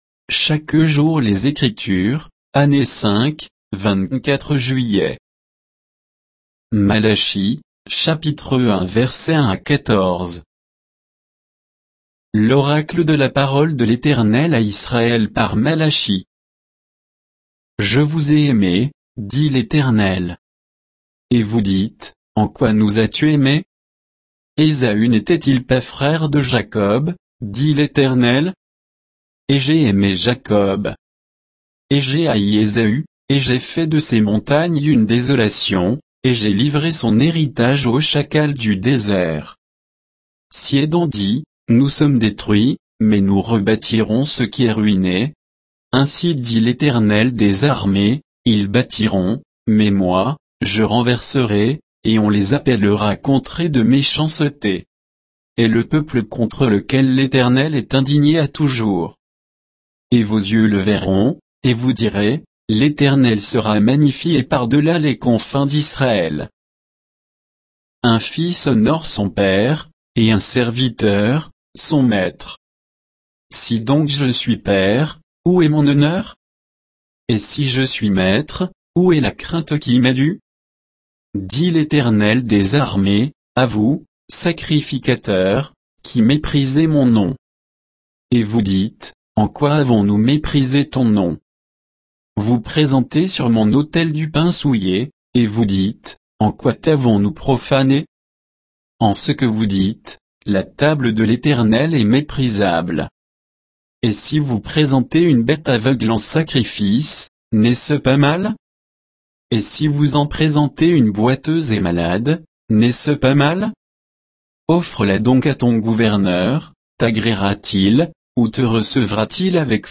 Méditation quoditienne de Chaque jour les Écritures sur Malachie 1, 1 à 14